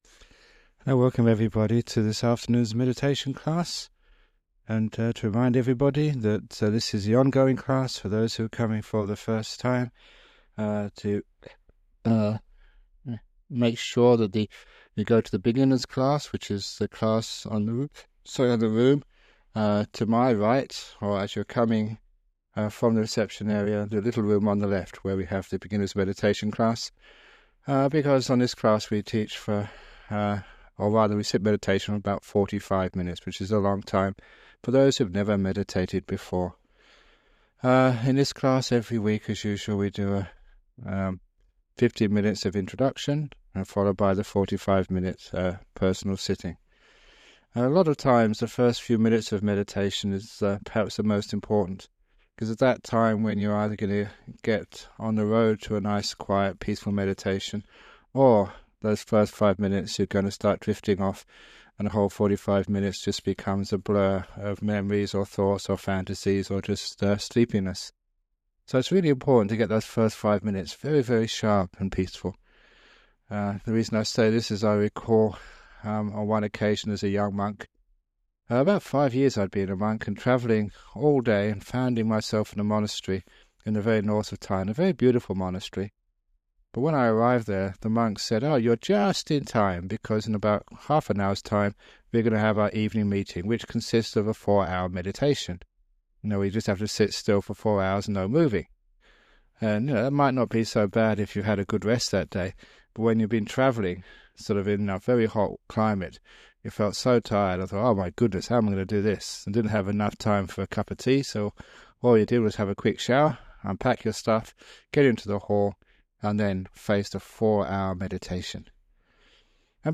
It includes a talk about some aspect of meditation followed by a 45 minute guided meditation (starting at the 13 minutes mark). This guided meditation has been remastered and published by the Everyday Dhamma Network, and will be of interest to people who have started meditation but are seeking guidance to take it deeper.